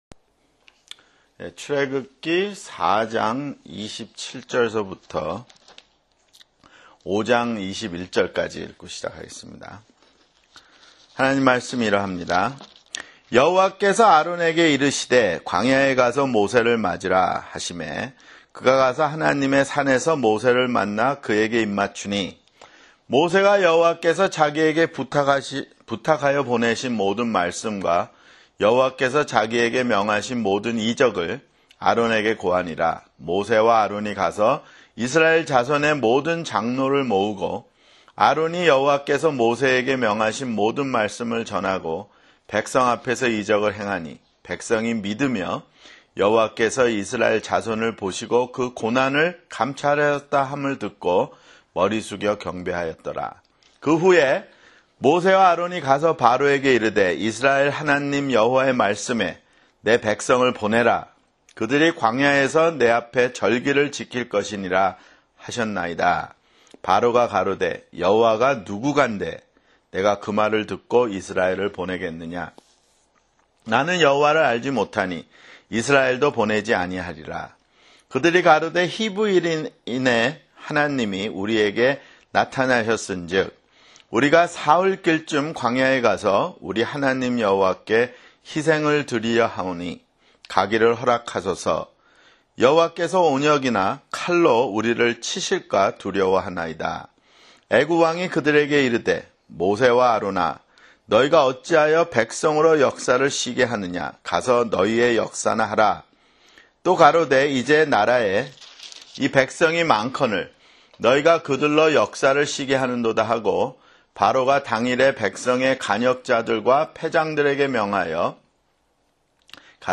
[성경공부] 출애굽기 (12)